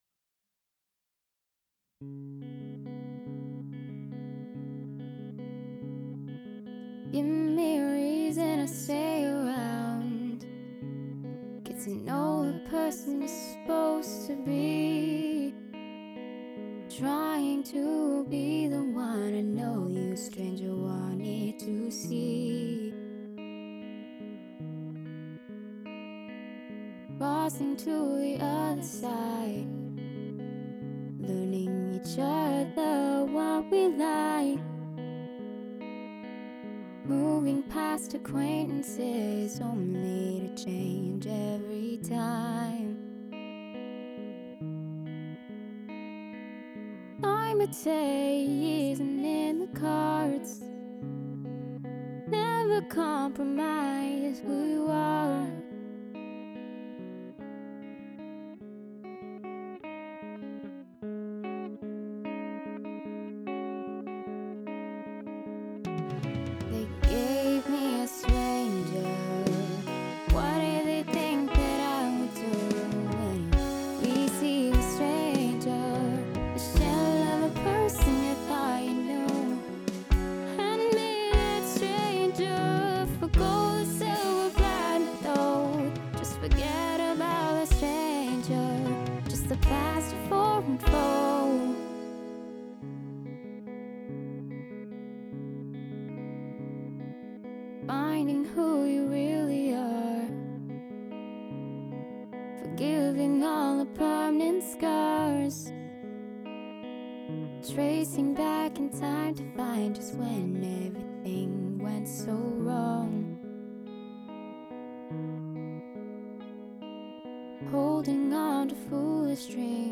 Hi all, I have given this about 4 hours of mixing, and think I did fairly well considering my experience doing this -- now it is time for critique.
I took a shot at this one, the hardest thing I found was removing all the noise on the vocal tracks, lots and lots of hiss and other noises, ended up splitting the .wav into little pieces to try to keep the track as quiet as I could, tuned the background vocals a bit.